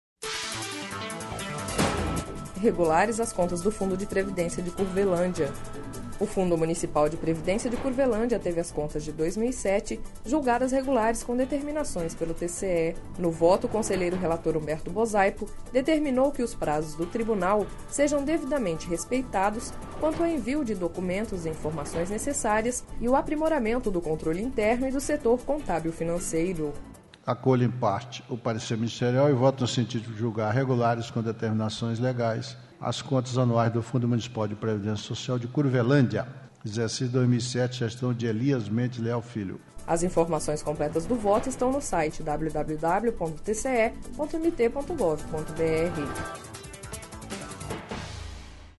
Sonora: Humberto Bosaipo– conselheiro do TCE-MT